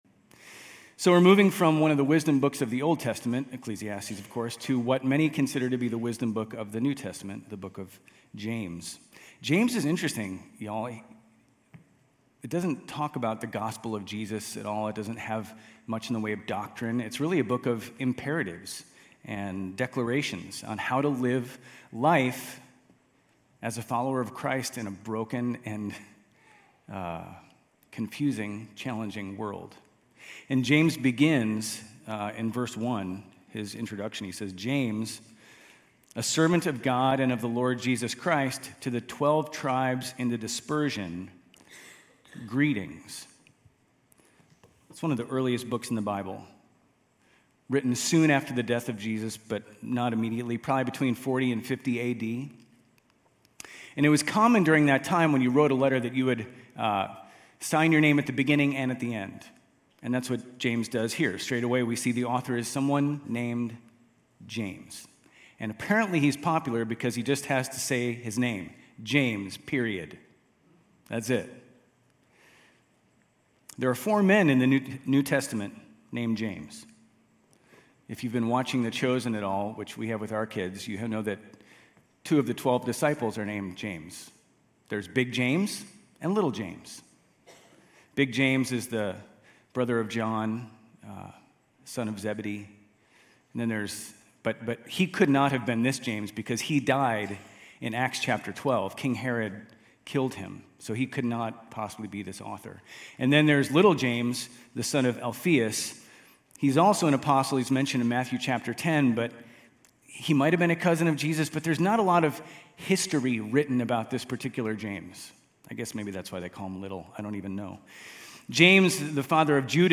GCC-UB-June-11-Sermon.mp3